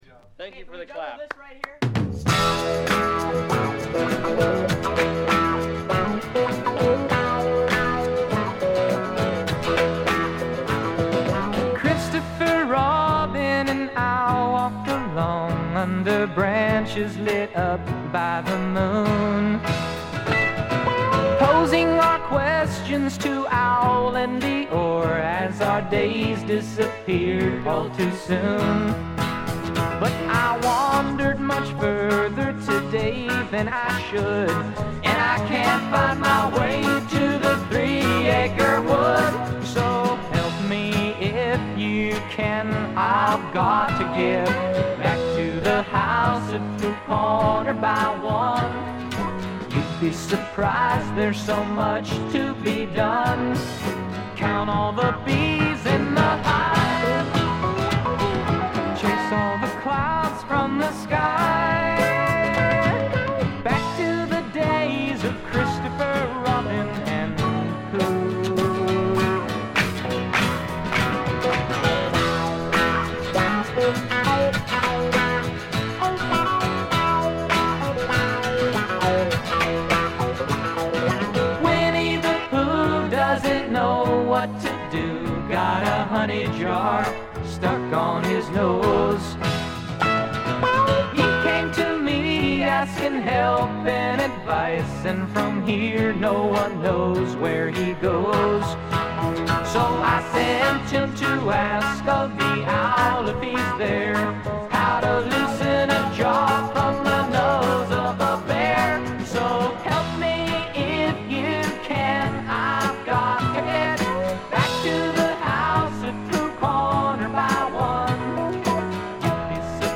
部分試聴ですが、チリプチ少々。
試聴曲は現品からの取り込み音源です。